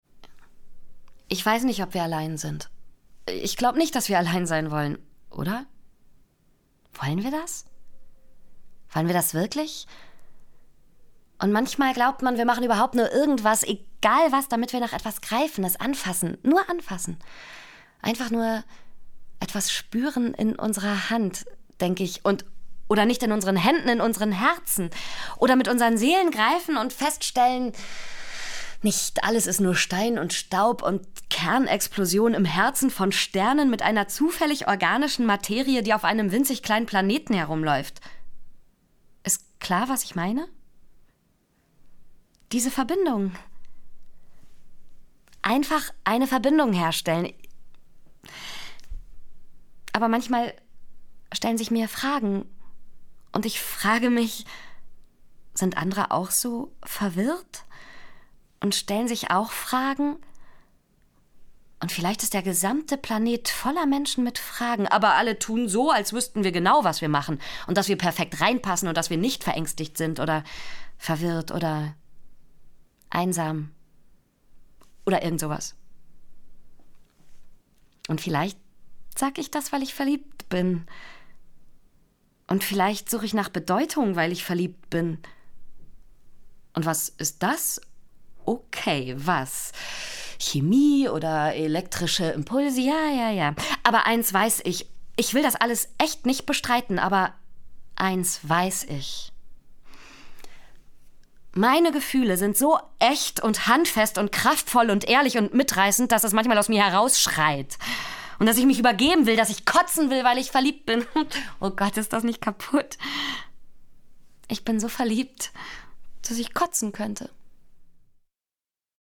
sehr variabel
Mittel plus (35-65)
Norddeutsch
Commercial (Werbung)